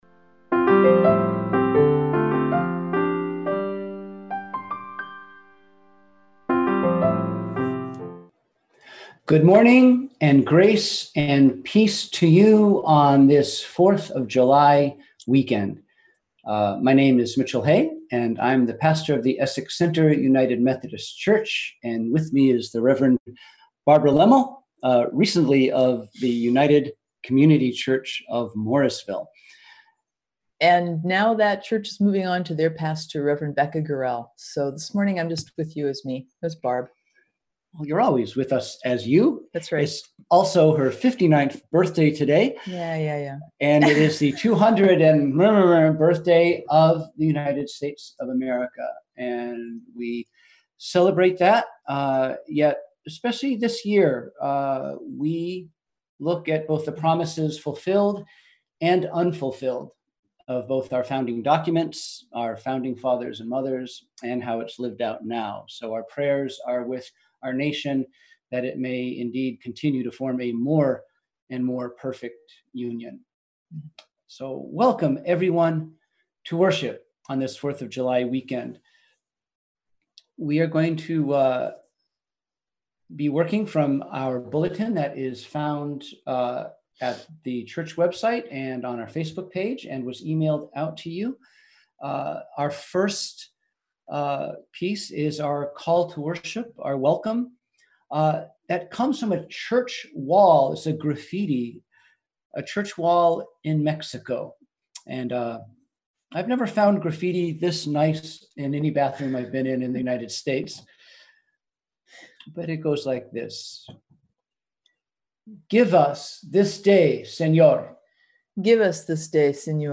We held virtual worship on Sunday, July 5, 2020 at 10am.